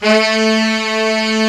SAX A2.wav